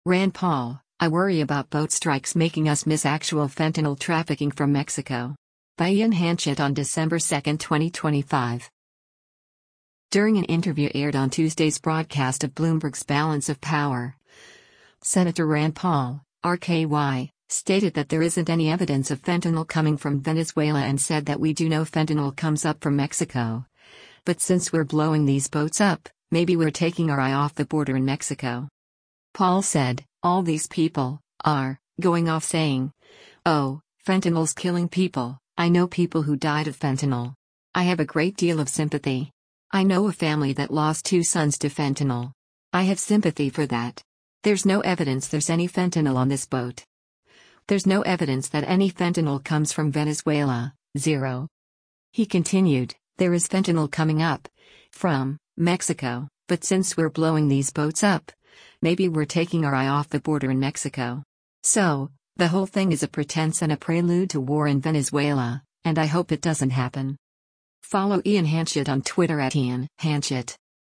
During an interview aired on Tuesday’s broadcast of Bloomberg’s “Balance of Power,” Sen. Rand Paul (R-KY) stated that there isn’t any evidence of fentanyl coming from Venezuela and said that we do know fentanyl comes up from Mexico, “but since we’re blowing these boats up, maybe we’re taking our eye off the border in Mexico.”